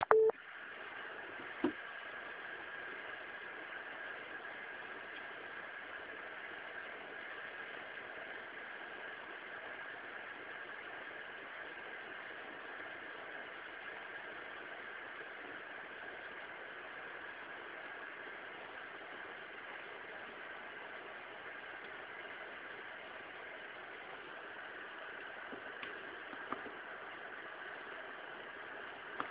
nagranie z wysokich obrotów. Dopóki nie opuściłem takiego miejsca chłodzenie pracowało głośniej, po przejściu w tereny z mniejszą trawą spadało do trybu cichego.
Jeśli miałbym porównać odczucia subiektywne dotyczące głośności wysokich obrotów to umieściłbym je gdzieś za Radeonem X1950XTX, a przed GeForce'm 7950GX2.
Recording_8800_fast.wav